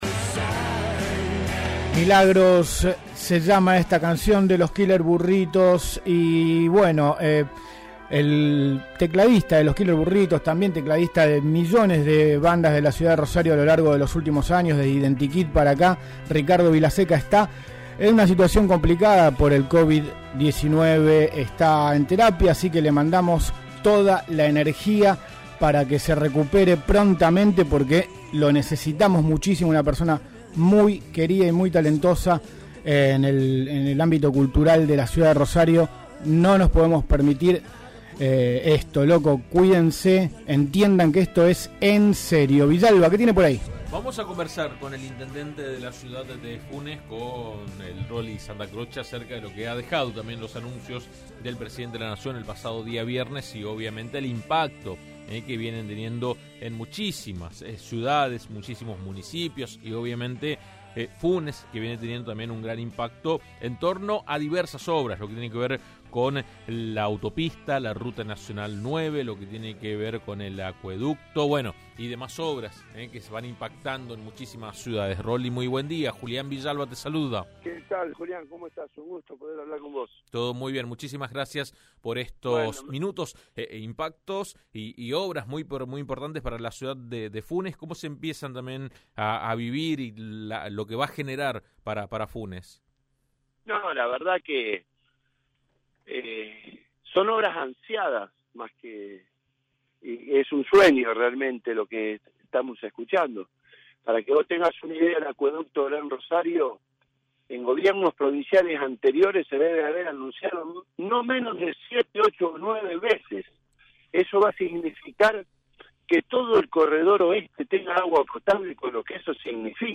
El intendente de Funes, Roly Santacroce, habló con AM 1330 y valoró muy positivamente el anuncio de la obra del Acueducto Gran Rosario y explicó otras obras importantes que tienen fuerte impacto en su ciudad.